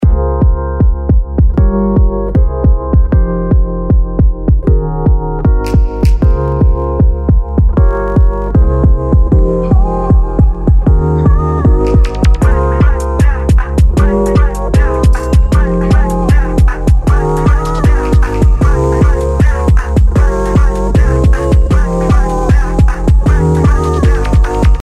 ズバリその名の通りVapor Waveのサウンドになるような効果ですが、とても精度高いです。